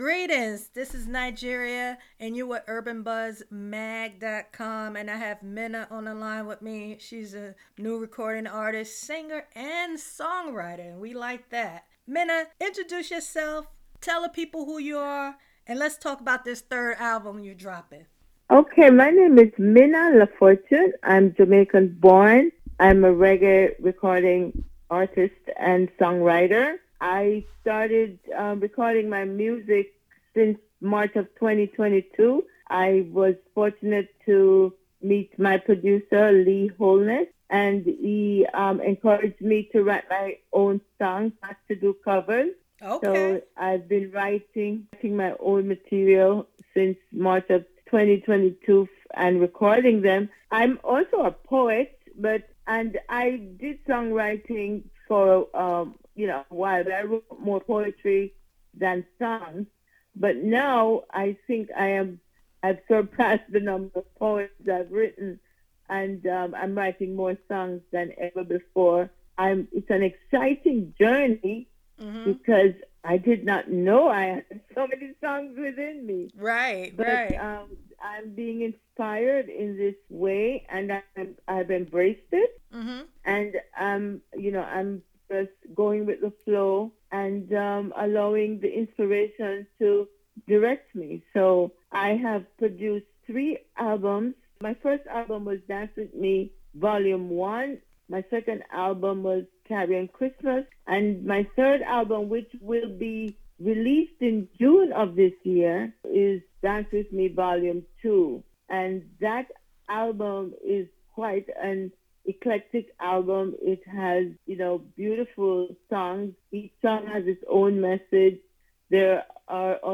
delivering conscious lyrics, love songs, and whining grooves.